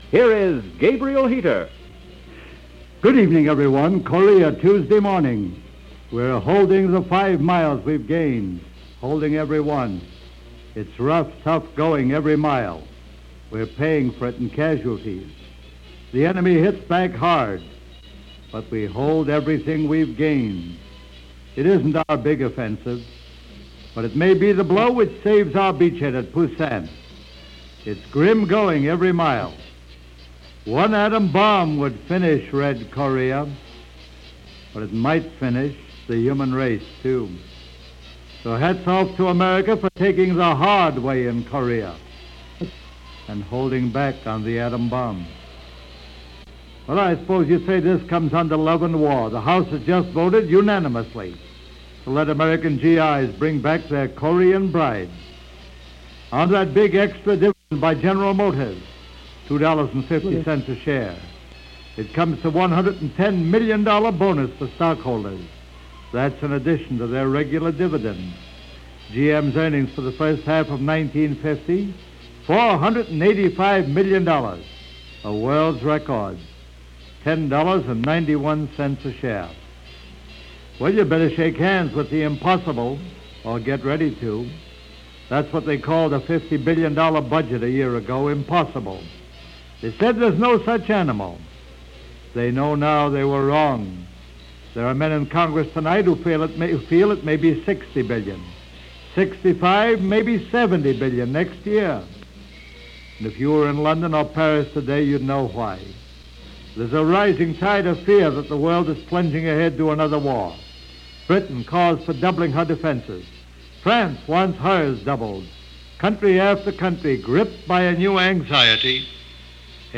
In fact, 67 years ago this month, as reported by Gabriel Heater during his newscast over The Mutual Broadcasting system, just such a suggestion was brought up during what was our previous military involvement in Korea.
This broadcast, with commentary by Gabriel Heater talks about the war and the possibilities of what would happen if the U.S. decided to drop the bomb on North Korea. The broadcast isn’t complete, but you get enough of an idea that what you’re hearing from 1950 is eerily similar to what we’re hearing in 2017.
Gabriel-Heater-August-8-1950.mp3